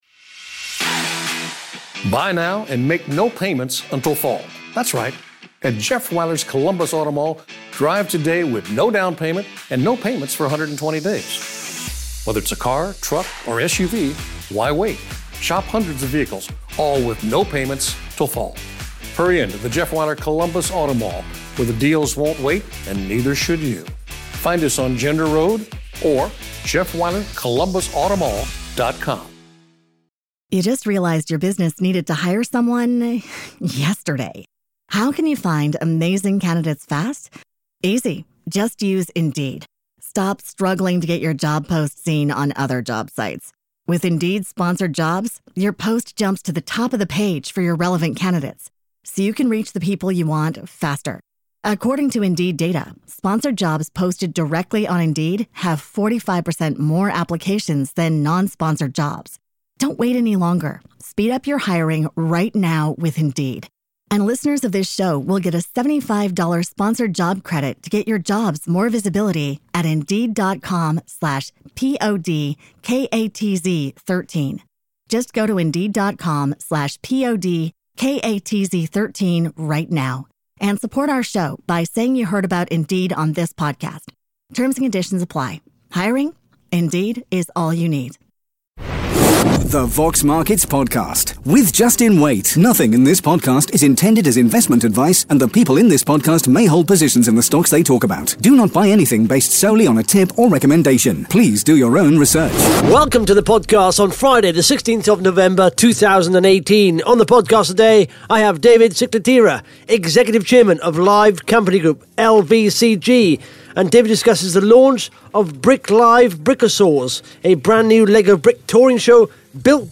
(Interview starts at 10 minutes)